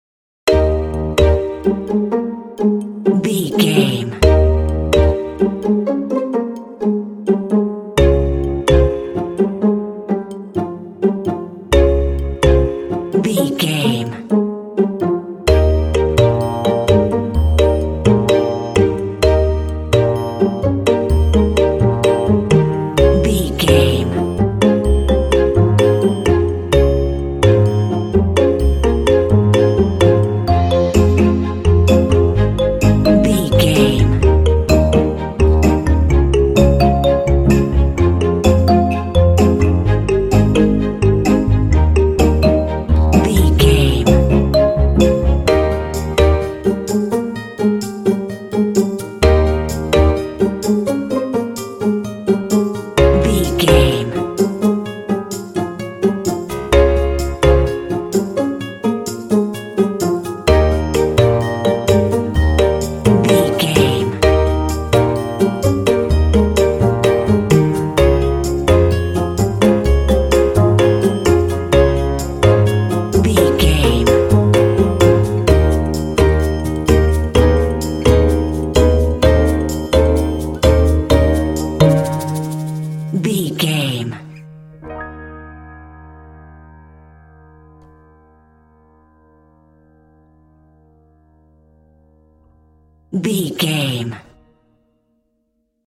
Ionian/Major
E♭
cheerful/happy
uplifting
dreamy
relaxed
strings
percussion
double bass
acoustic guitar
piano
contemporary underscore